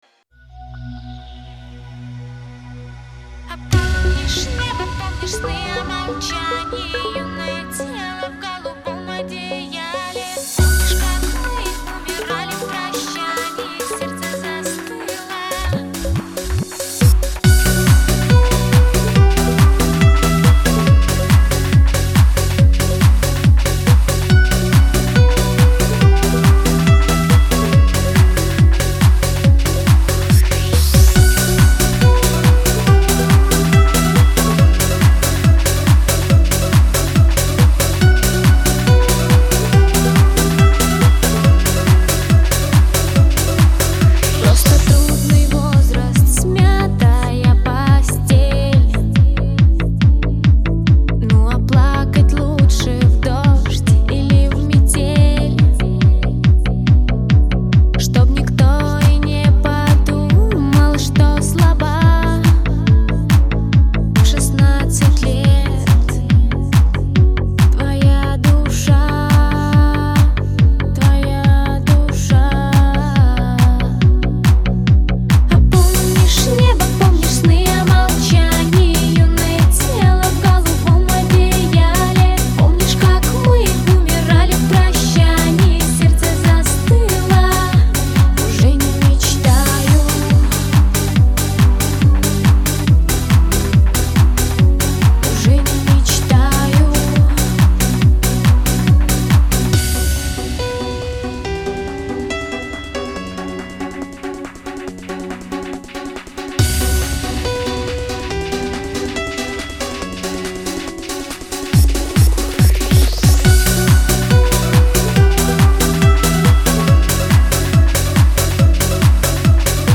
Жанр: Поп Продолжительность: 00:47:21 Список композиций: